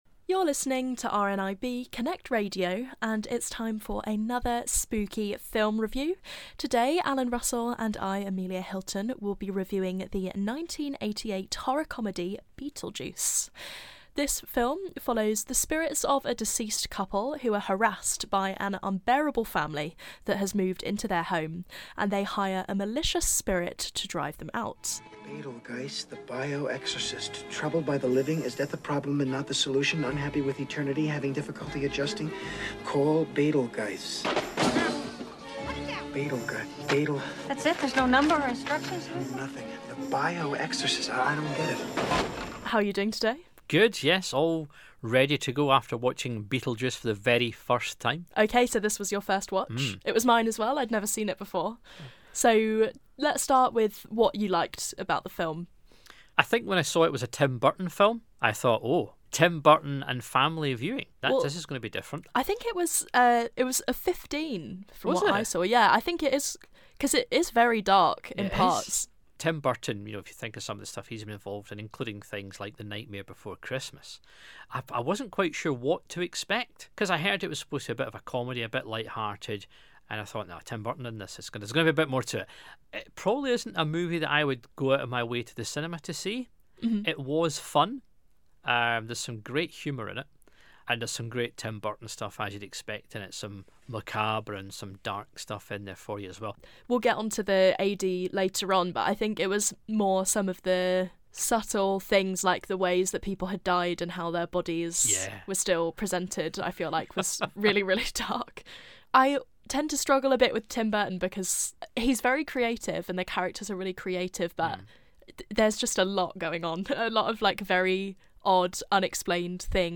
Beetlejuice Film Review